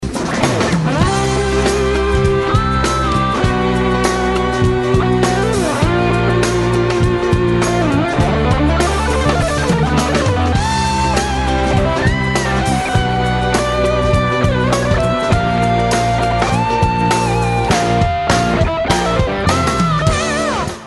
V 10 sekunde je to falosne. Zvuk je trochu zastrety, ale inak pekny. Aj to solo je pekne.